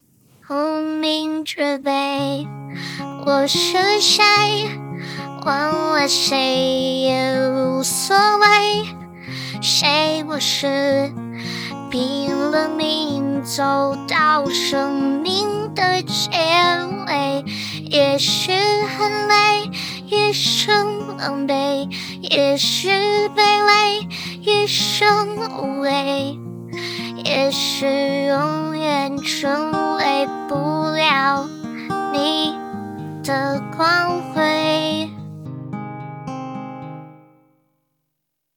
唱歌表现
女生模型模型工坊精品模型